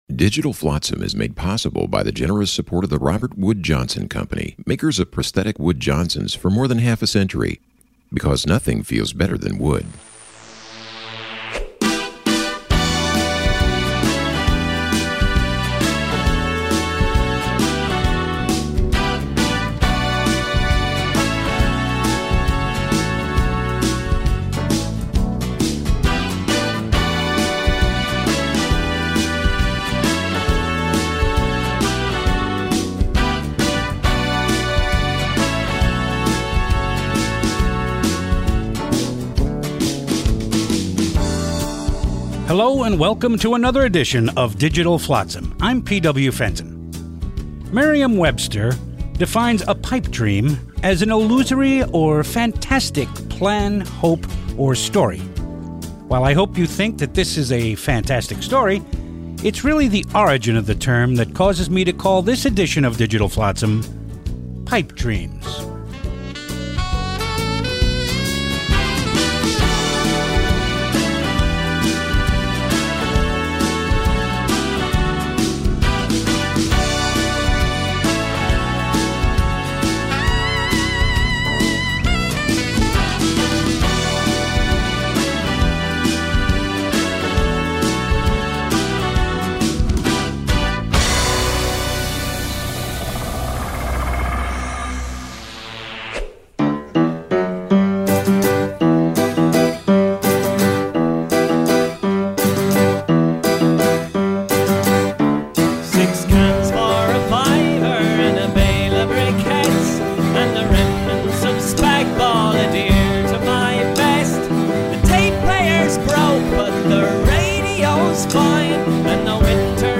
It appeared for several years nationally on Sirius Satellite Radio. We are proud to offer these great spoken word pieces again.